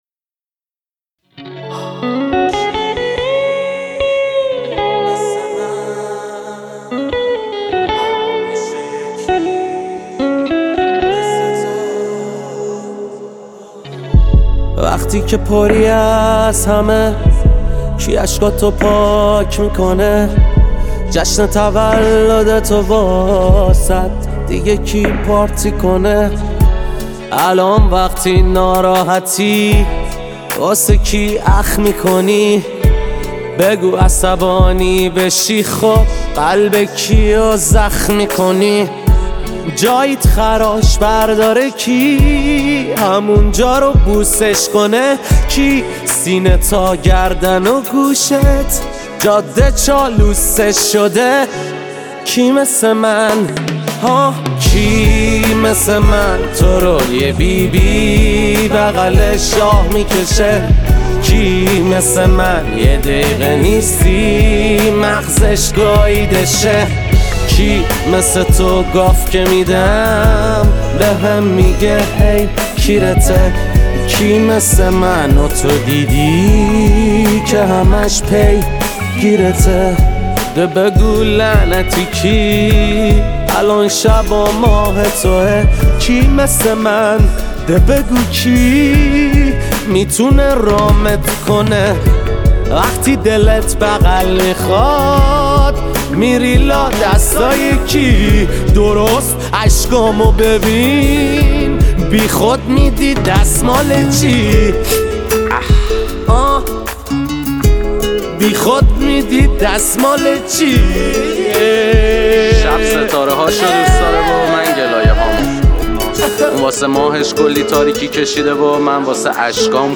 آراَندبی